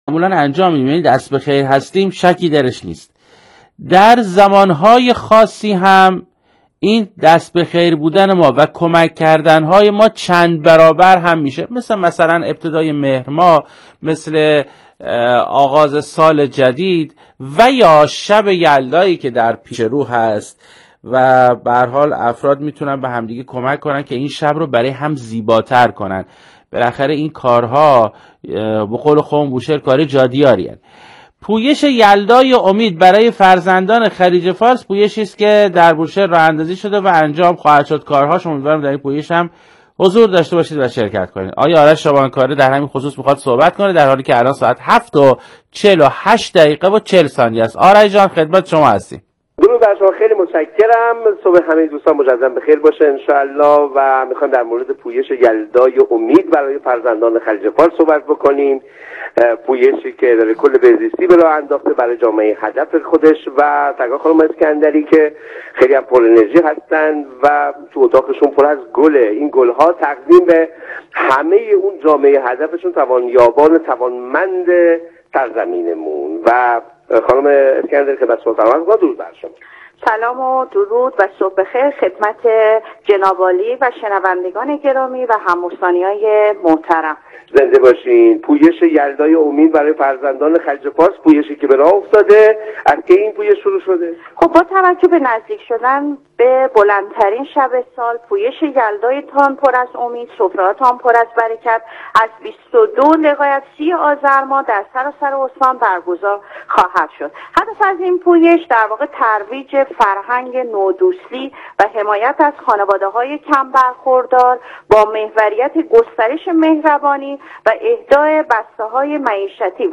به گزارش روابط عمومی اداره کل بهزیستی استان بوشهر، مهرناز اسکندری، مدیرکل بهزیستی استان بوشهر، در گفت‌وگوی زنده و پرشنونده رادیویی سلام صدای مرکز بوشهر، با عنوان «پویش یلدای امید» از آغاز این حرکت انسان‌دوستانه خبر داد و اظهار داشت: با نزدیک شدن به بلندترین شب سال، پویش یلدای امید با هدف گسترش مهربانی، لبخند و امید در میان فرزندان بهزیستی از بیست و دوم آذرماه در سراسر استان آغاز می‌شود.